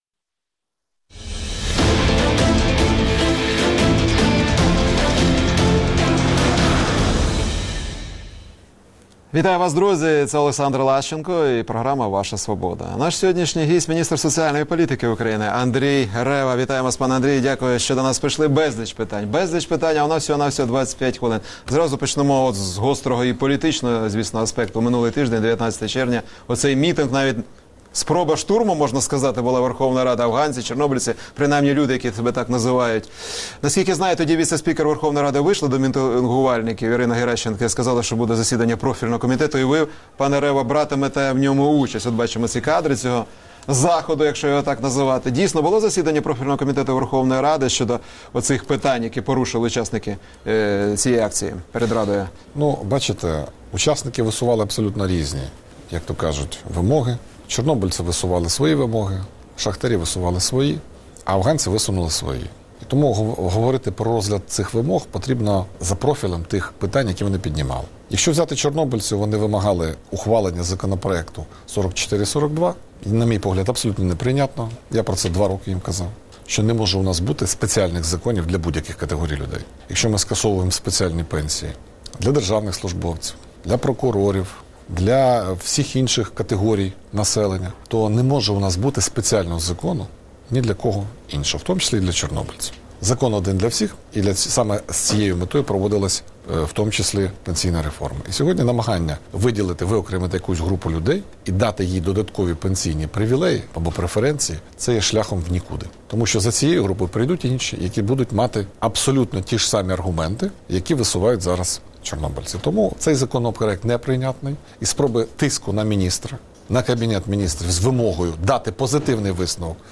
Ваша Свобода | Пільговики, «мінімалка», монетизація субсидій. Інтерв’ю з міністром соцполітики УкраЇни